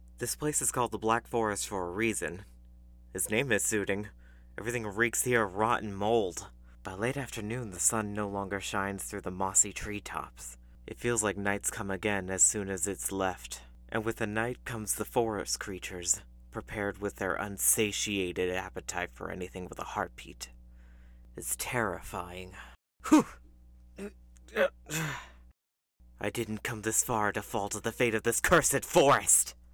The People Freer - Gritty, measured
North American (General), North American (US New York, New Jersey, Bronx, Brooklyn), North American (US South), North American (Mid-Atlantic), British (England - East Midlands)